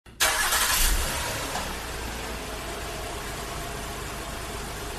Car Engine Start
Car Engine Start is a free sfx sound effect available for download in MP3 format.
018_car_engine_start.mp3